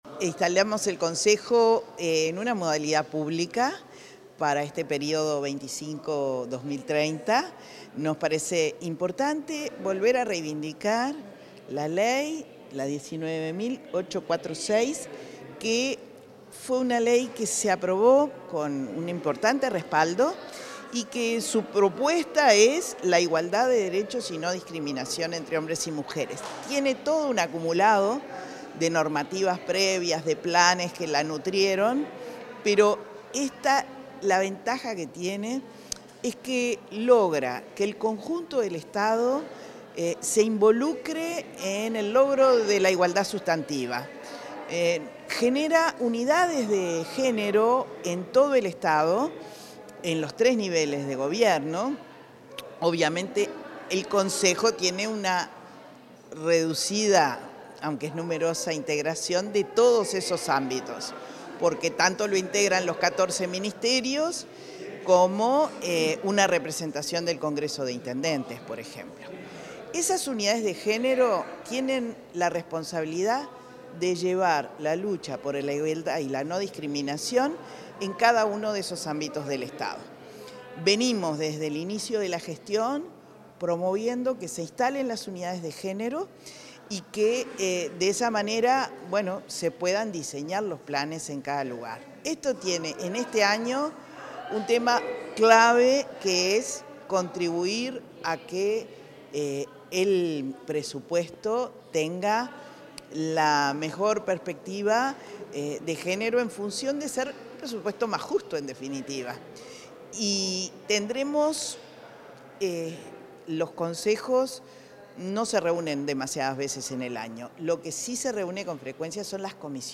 Declaraciones de la directora de Inmujeres, Mónica Xavier
Declaraciones de la directora de Inmujeres, Mónica Xavier 23/07/2025 Compartir Facebook X Copiar enlace WhatsApp LinkedIn Previo al acto de reinstalación del Consejo Nacional de Género para el período 2025-2030, la directora del Instituto Nacional de las Mujeres (Inmujeres), Mónica Xavier, efectuó declaraciones.